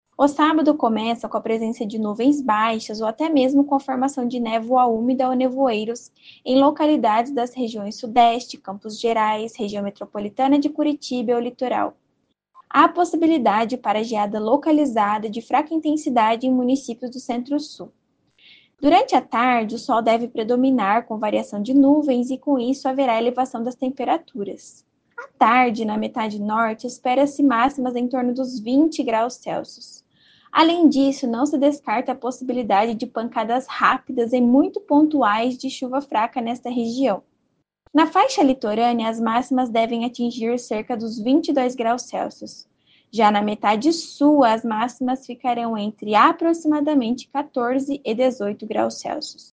Ouça o que diz a meteorologista